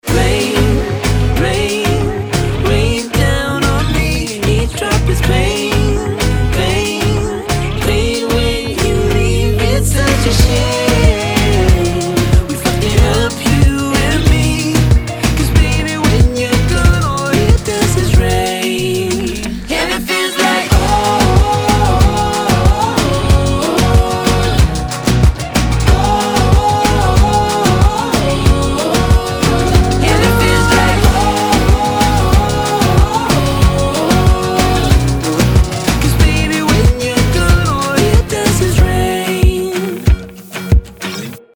• Качество: 320, Stereo
мужской вокал
dance
приятные
Pop Rock
alternative
vocal